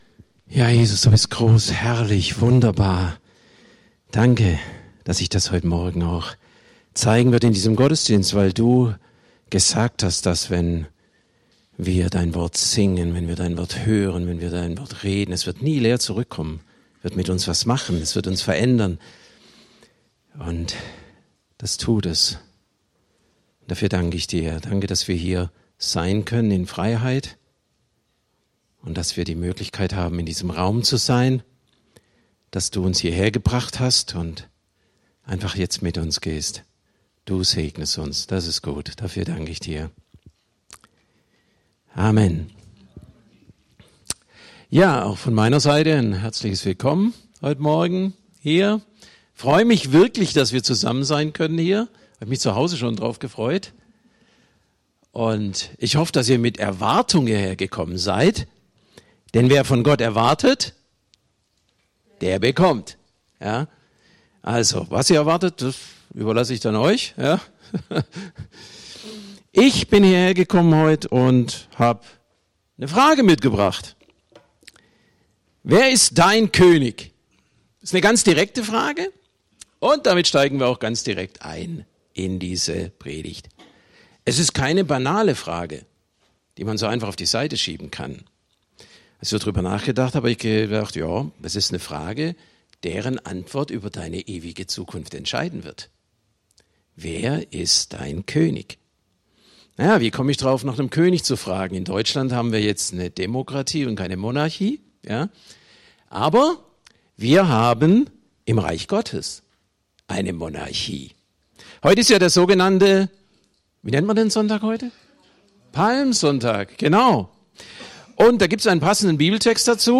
Predigt vom 13.04.2025